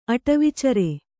♪ aṭavicare